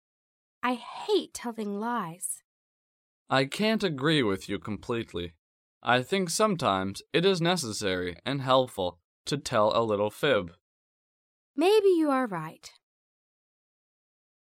第一， 迷你对话